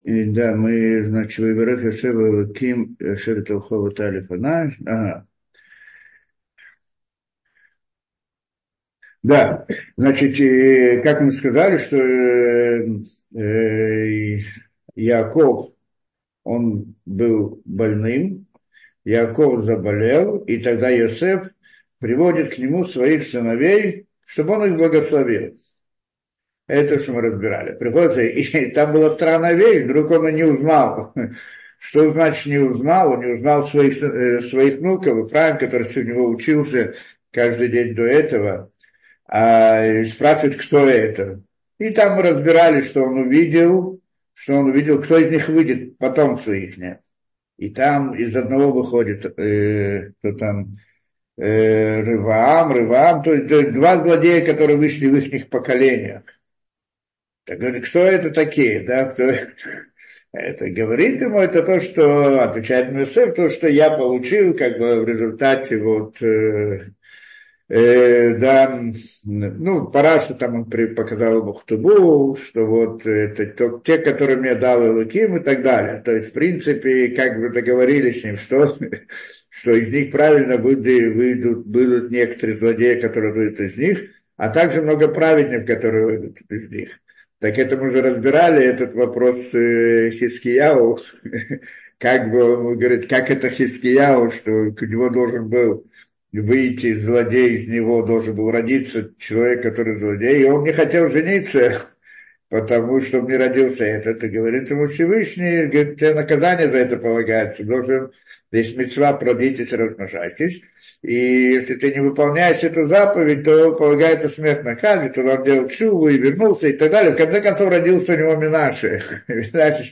Ваехи. Снисхождение и восхождение миров — слушать лекции раввинов онлайн | Еврейские аудиоуроки по теме «Недельная глава» на Толдот.ру